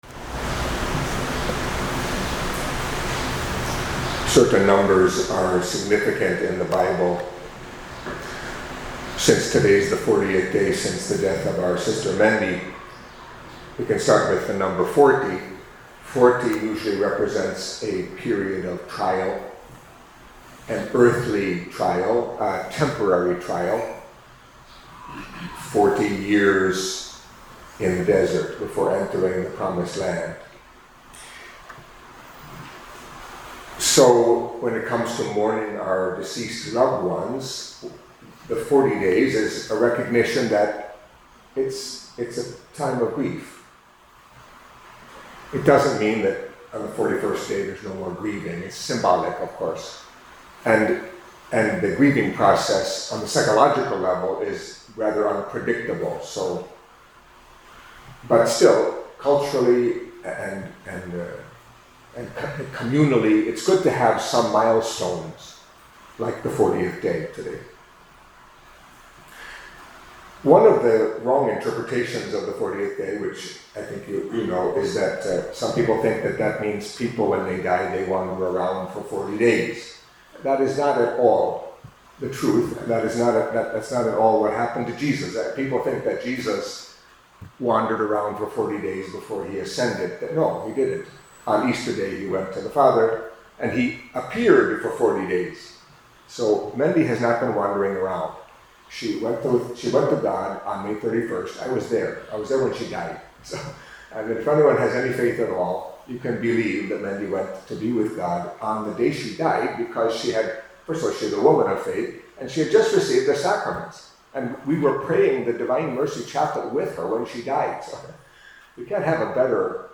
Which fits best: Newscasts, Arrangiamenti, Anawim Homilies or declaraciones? Anawim Homilies